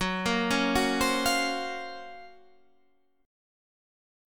F#M#11 chord